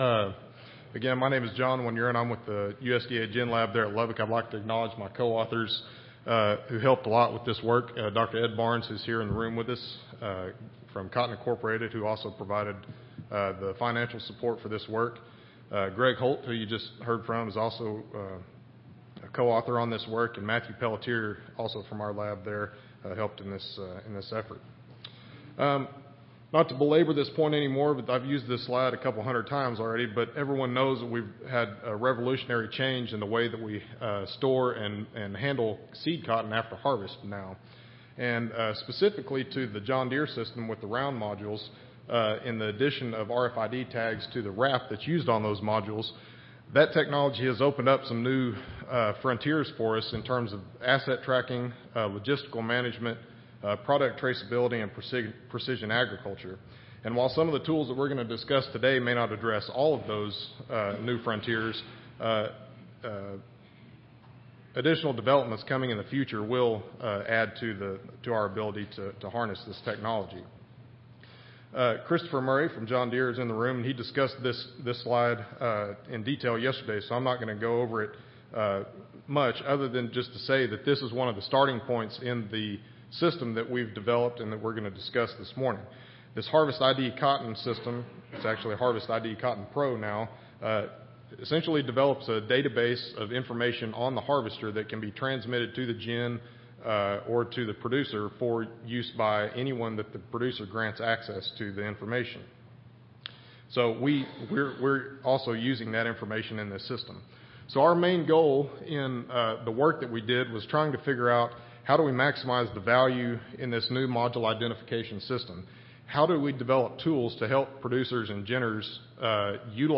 Recorded Presentation A new system for managing cotton modules was developed.